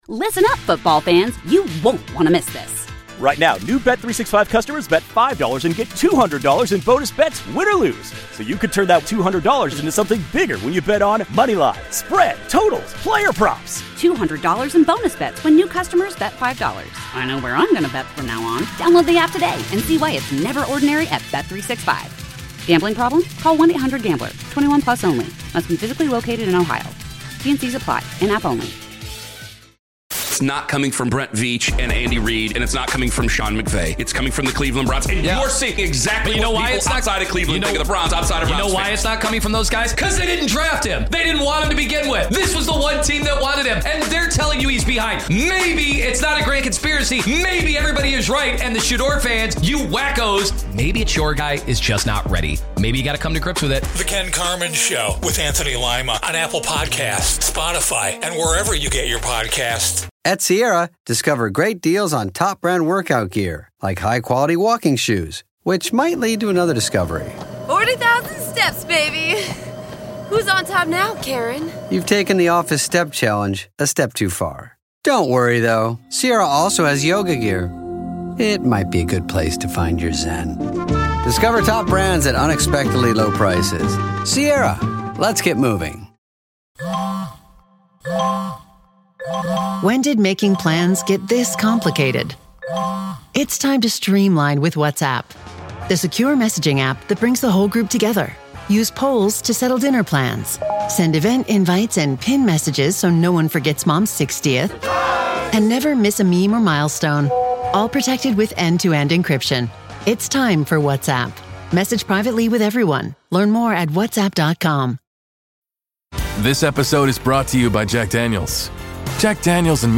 Buffalo’s go-to sports talk podcast for fans who want real opinions, smart analysis, and plenty of laughs along the way.